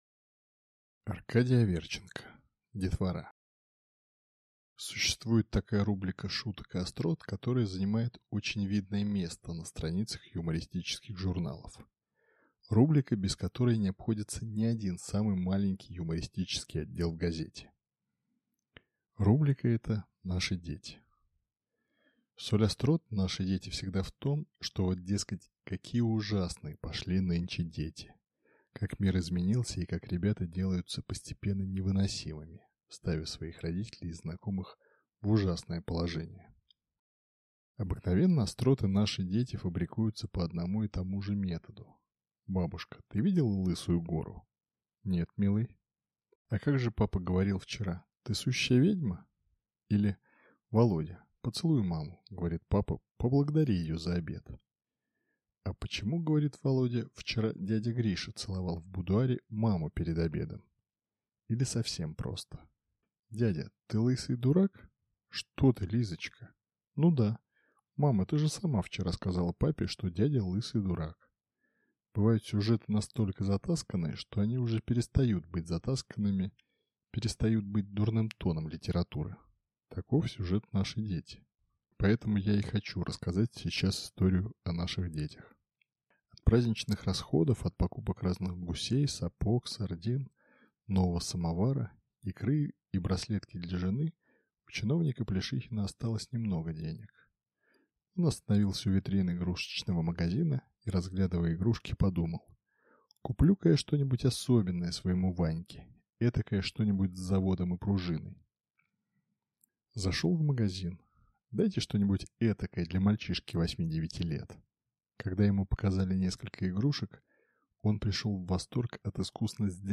Aудиокнига
Читает аудиокнигу